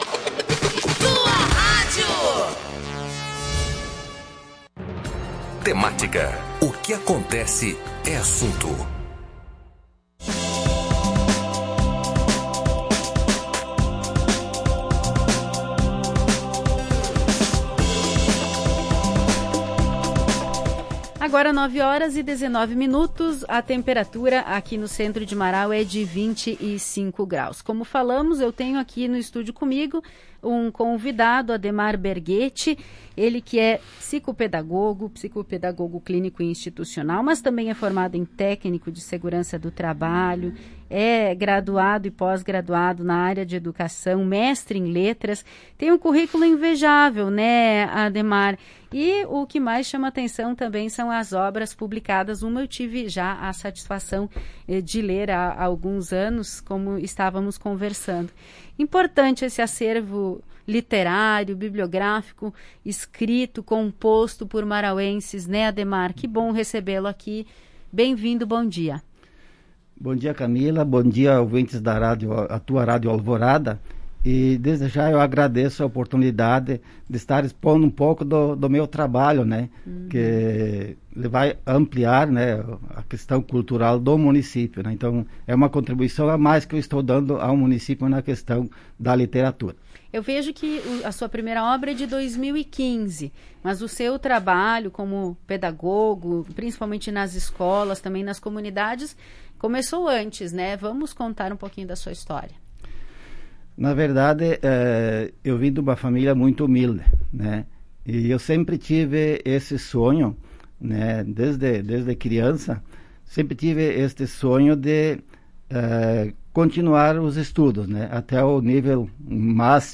Em entrevista à Tua Rádio Alvorada